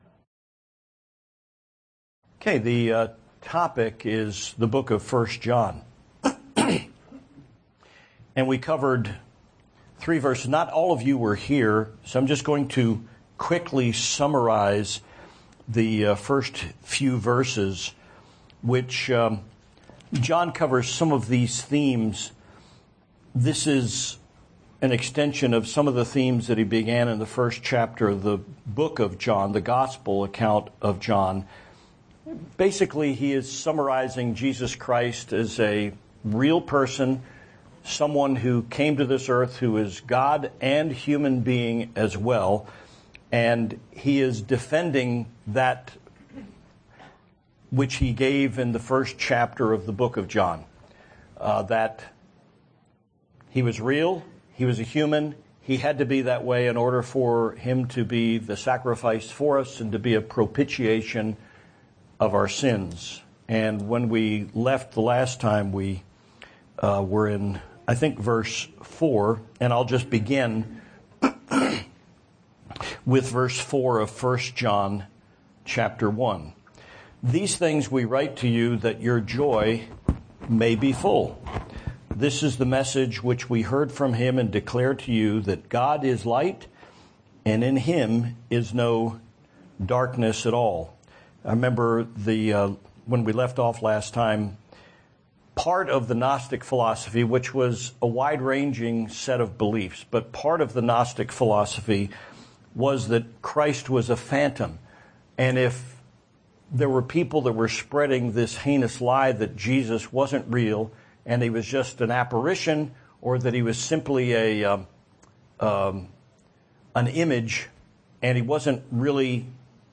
1John Chapters 1 and 2 Study